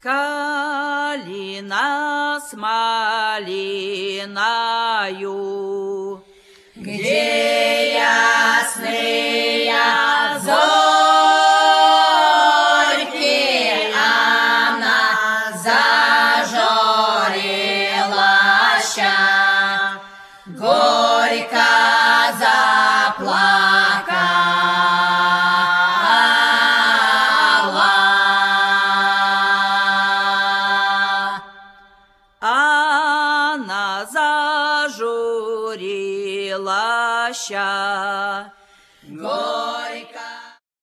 pie¶ń wojacka
The folk band of Russian Orthodox Old Believers
¶piew vocal